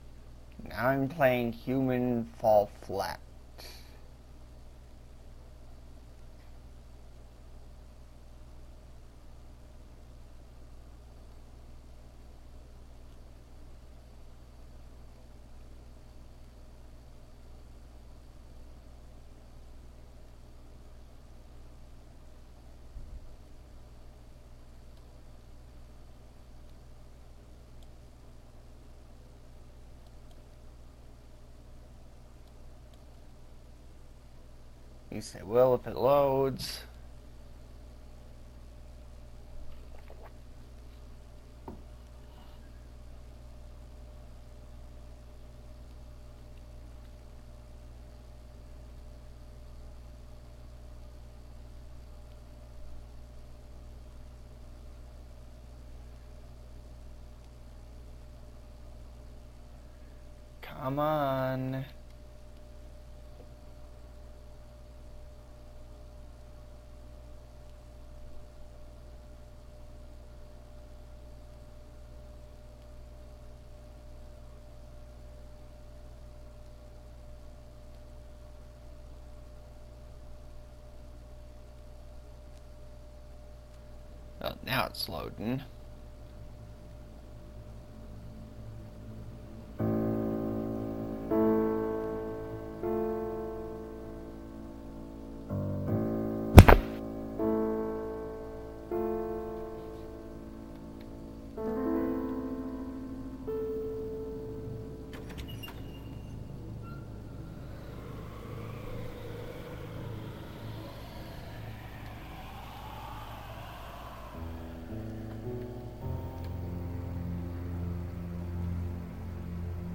I play Human Fall Flat with commentary